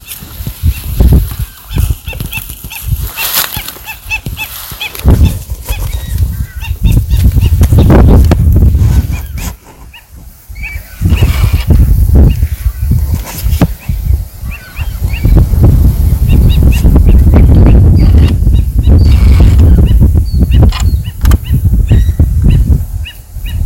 Black-necked Stilt (Himantopus mexicanus)
Condition: Wild
Certainty: Observed, Recorded vocal